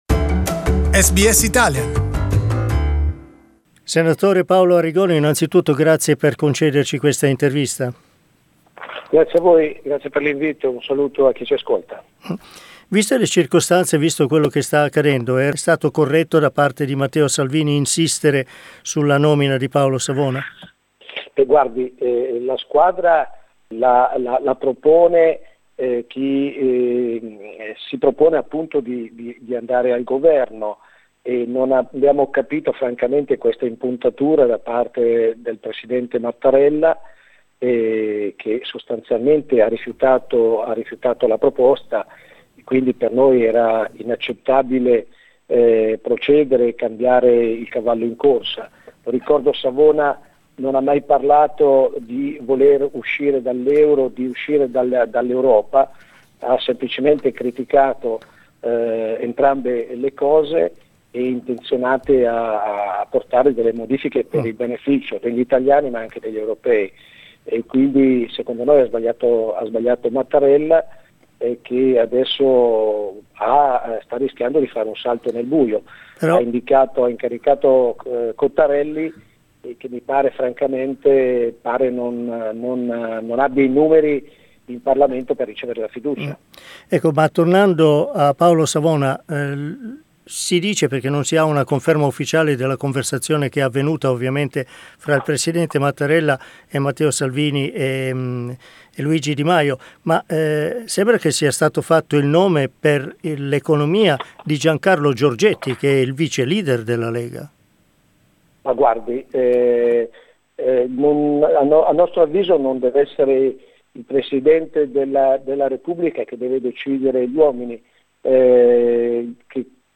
Italian Senator Paolo Arrigoni talks about the current political crisis in Italy that has had widespread effects on global markets.